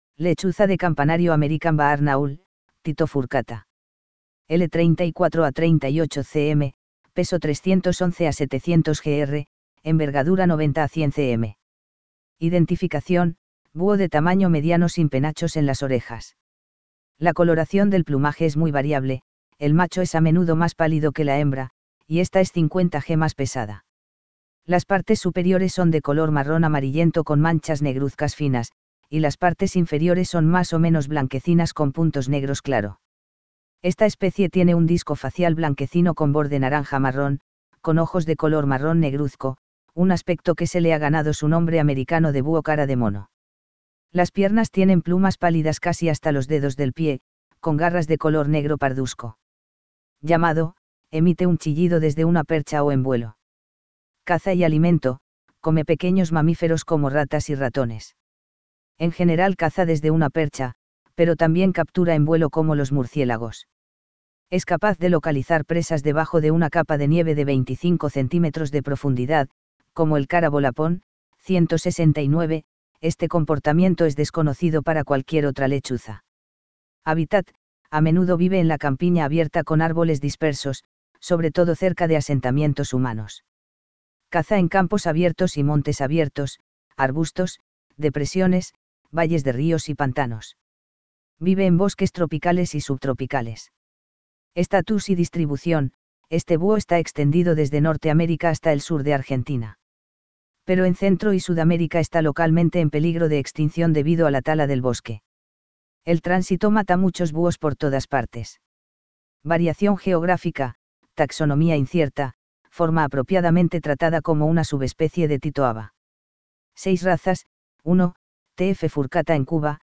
Llamado: emite un chillido desde una percha o en vuelo.
Lechuza de campanario.mp3